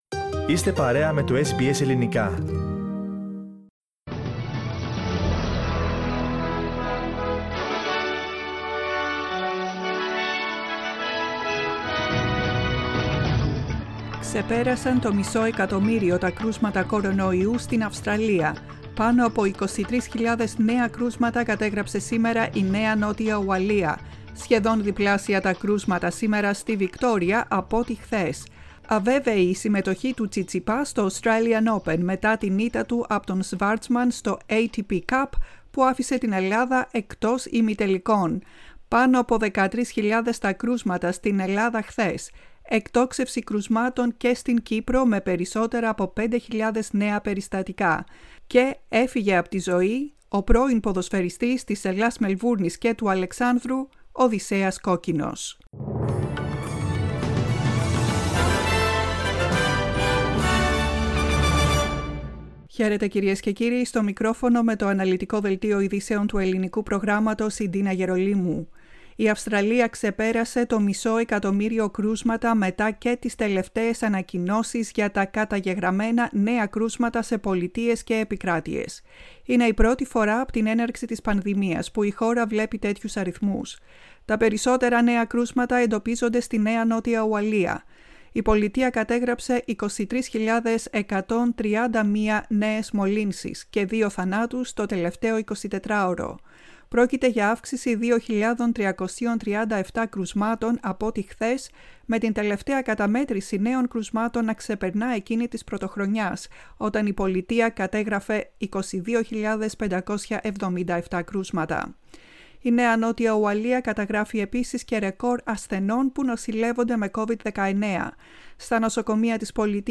Listen to the main bulletin in Greek for Tuesday 04.01.2022
News in Greek.